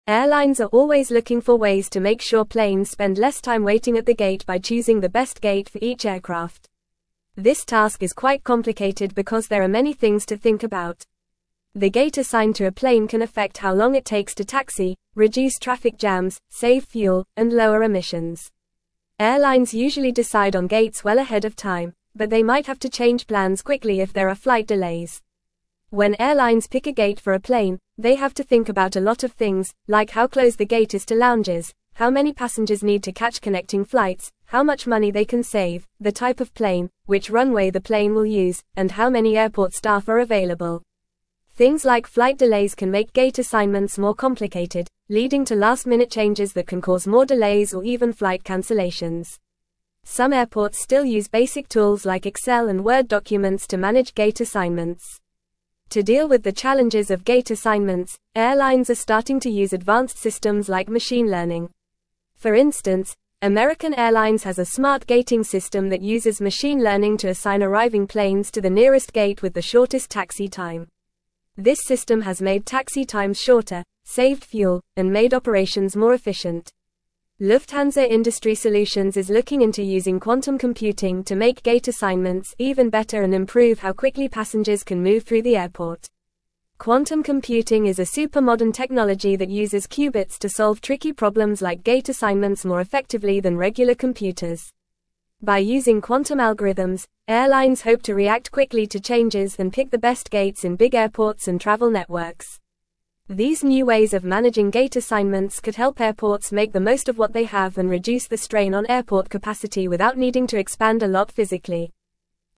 Fast
English-Newsroom-Upper-Intermediate-FAST-Reading-Efficient-Gate-Allocation-in-Airports-Innovations-and-Benefits.mp3